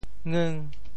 “鄞”字用潮州话怎么说？
鄞 部首拼音 部首 阝 总笔划 13 部外笔划 11 普通话 yín 潮州发音 潮州 ngeng5 文 中文解释 鄞 <名> 古地名 [Yin county]。